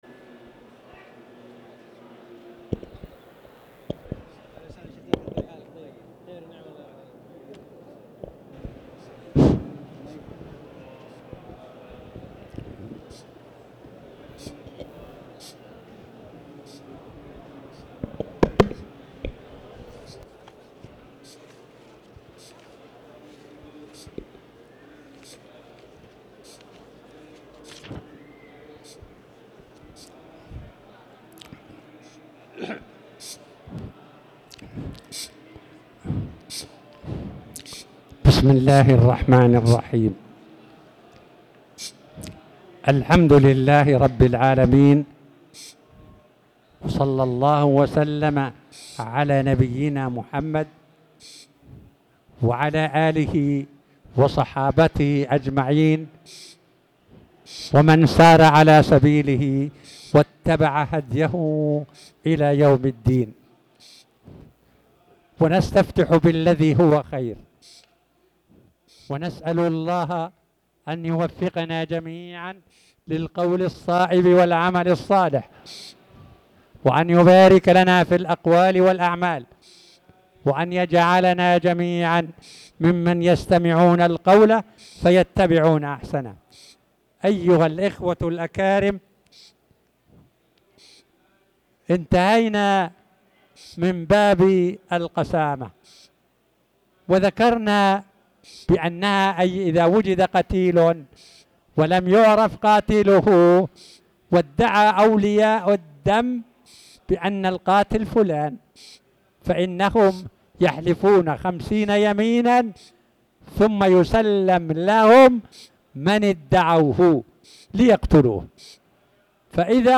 تاريخ النشر ١٣ صفر ١٤٤٠ هـ المكان: المسجد الحرام الشيخ